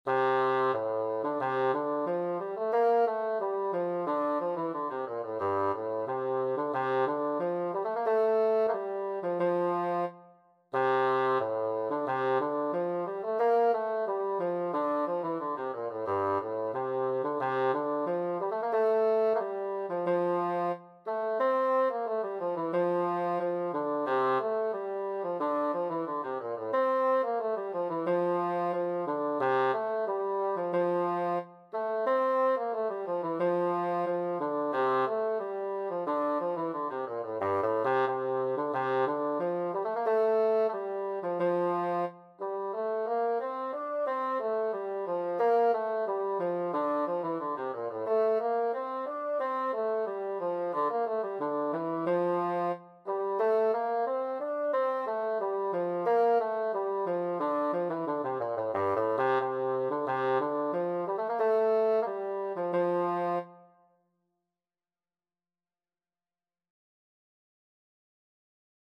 Bassoon version
4/4 (View more 4/4 Music)
F major (Sounding Pitch) (View more F major Music for Bassoon )
Andante = c. 90
G3-D5
Bassoon  (View more Intermediate Bassoon Music)
Traditional (View more Traditional Bassoon Music)